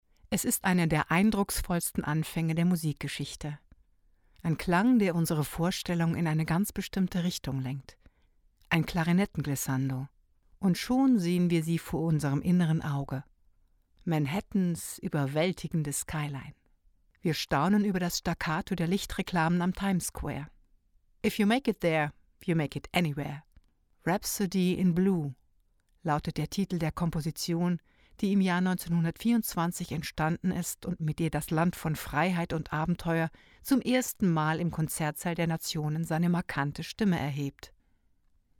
Musik-doku über